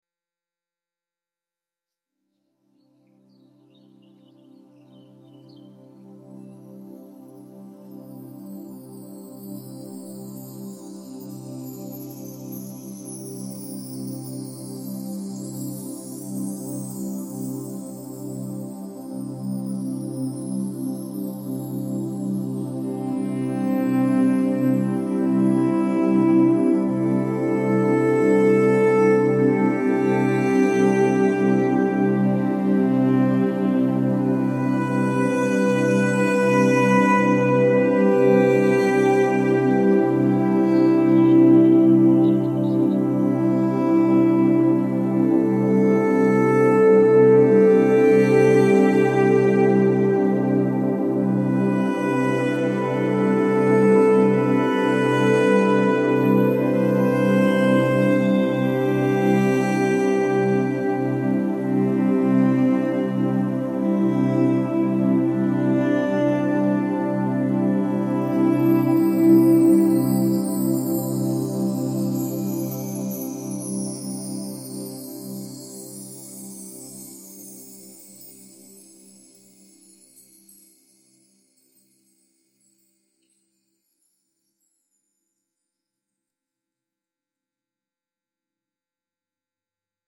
ambient nature soundscape with distant wind chimes and soft strings